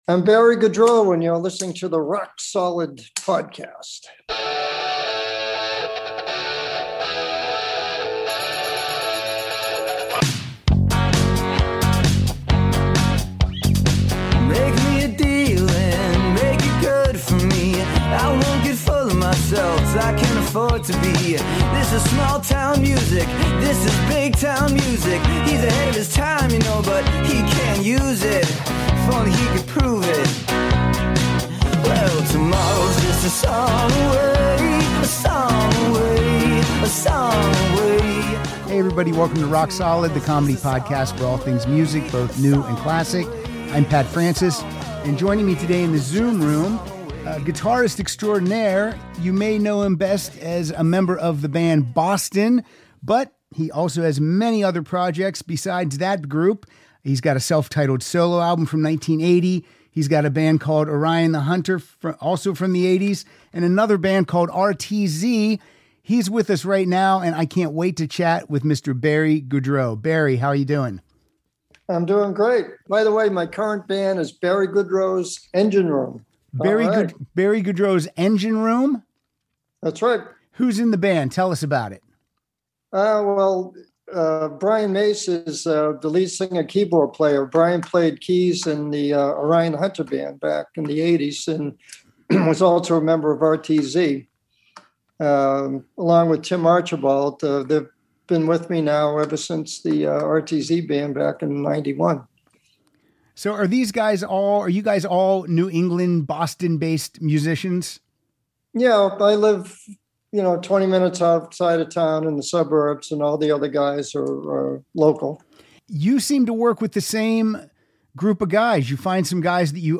welcomes guitarist Barry Goudreau to the Zoom Room to discuss his career as a member of Boston, Orion The Hunter, RTZ and his new band Barry Goudreau's Engine Room.